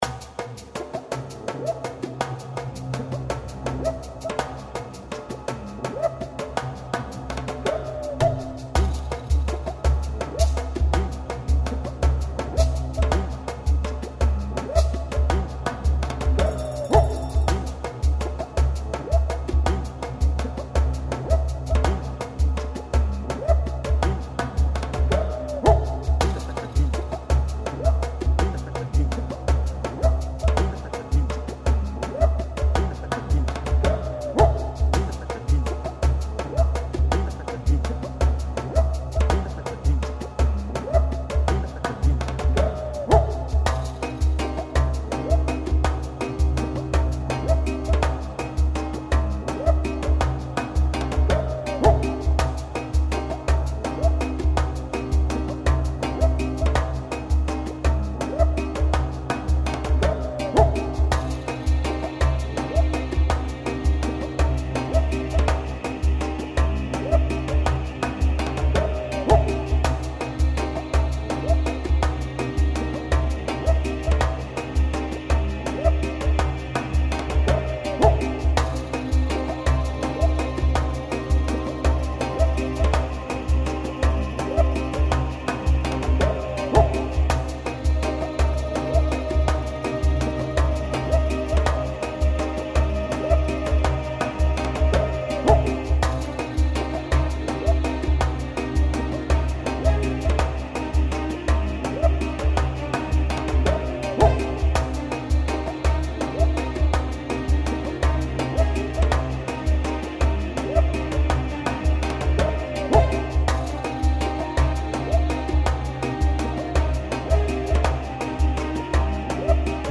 mefiance - ensemble instruments ethniques